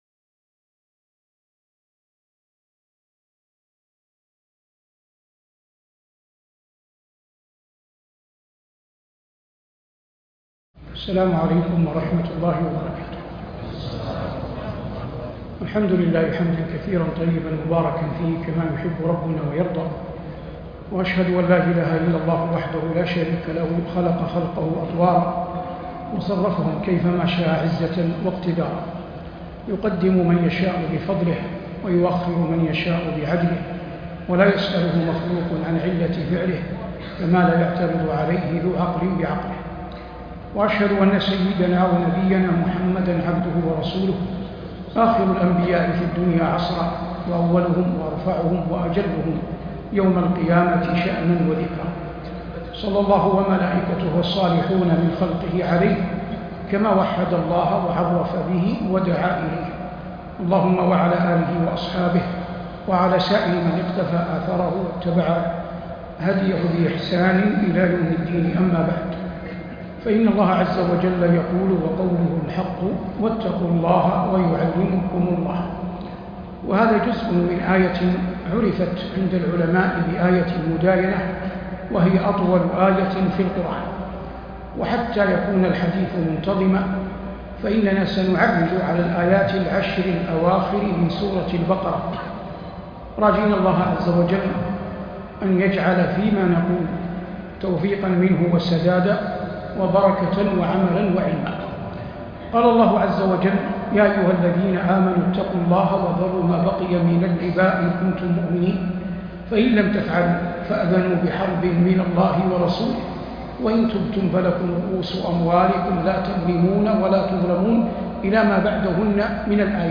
محاضرة واتقوا الله ويعلمكم الله بجامع الشيخ محمد بن راشد بالرياض - الشيخ صالح بن عواد المغامسى